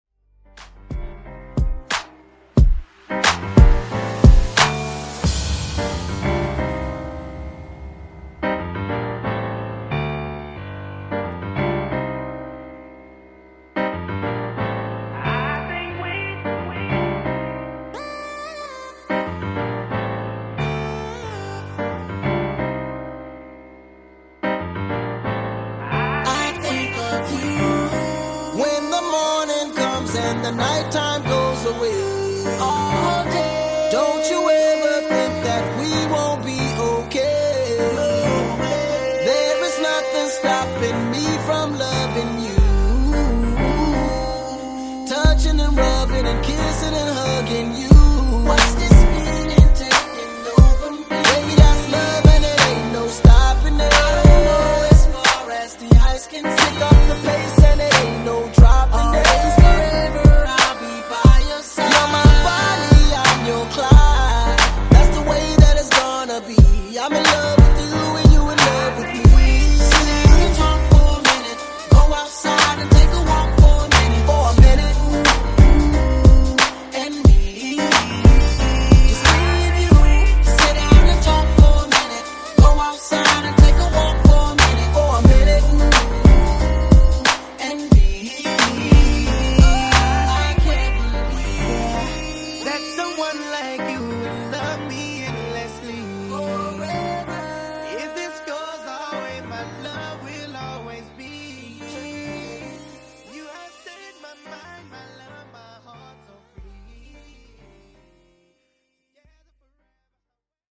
Genre: RE-DRUM Version: Clean BPM: 99 Time